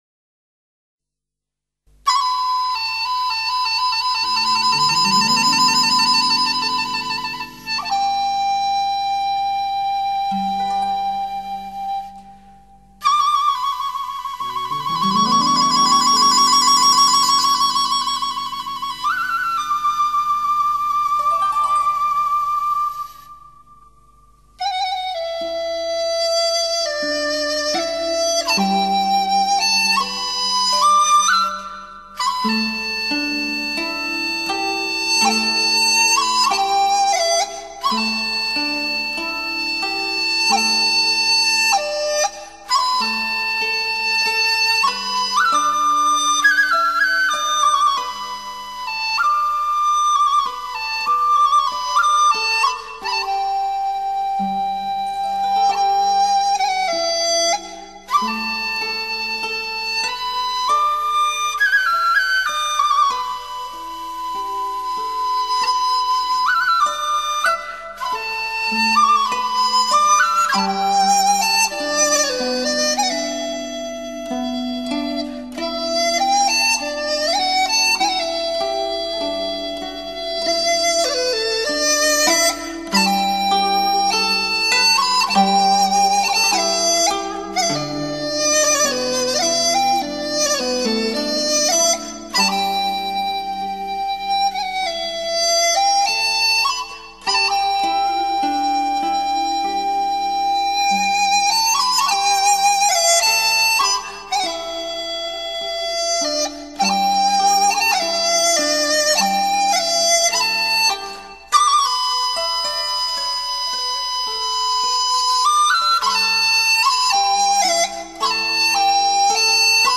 本碟收录了我国优秀的民族乐曲，淡而清雅，